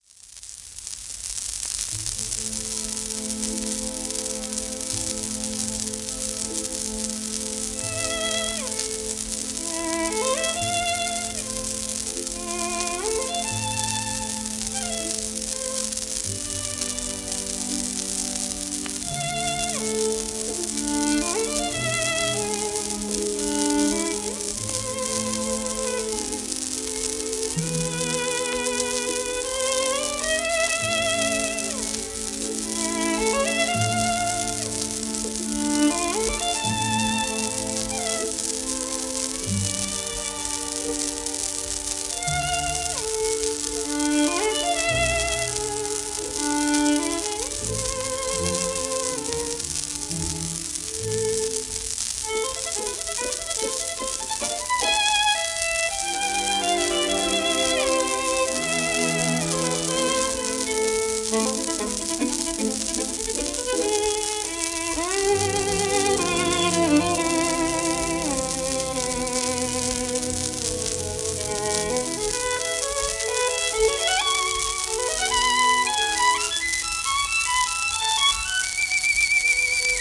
1935年英国録音